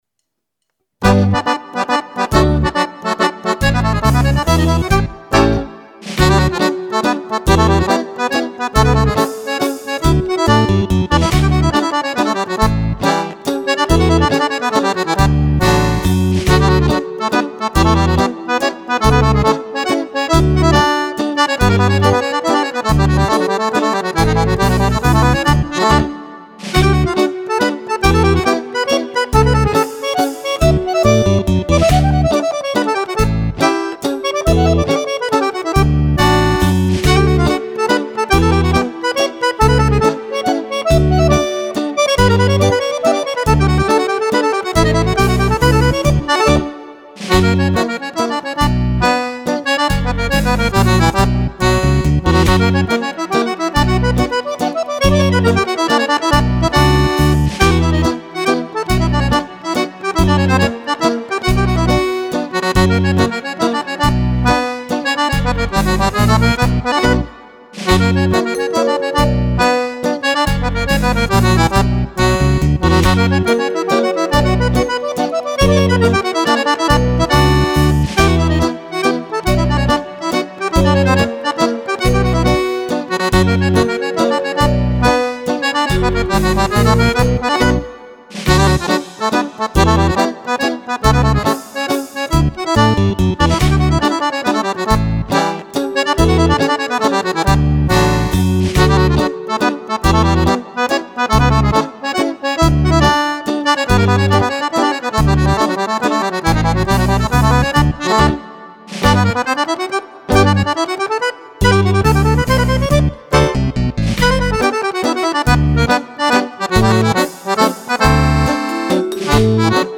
Mazurca
10 BALLABILI PER FISARMONICA